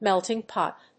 アクセントmélting pòt